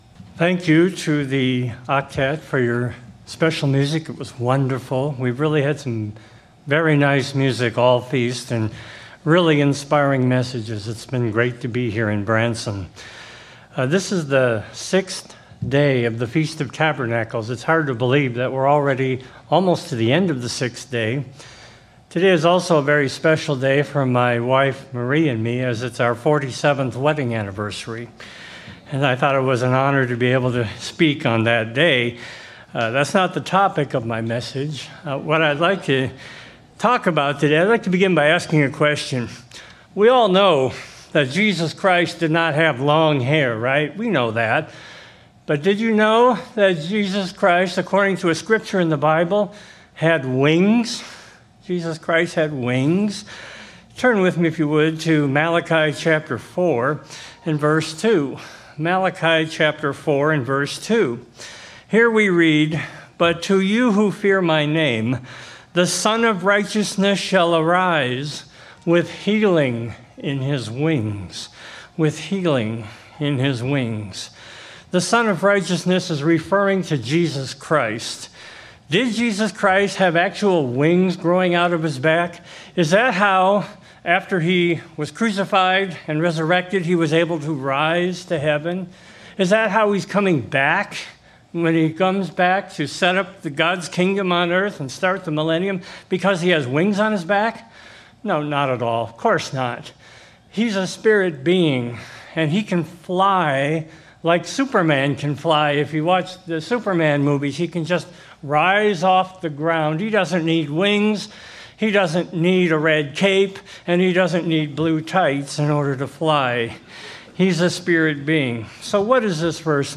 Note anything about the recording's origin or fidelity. Given in Branson, Missouri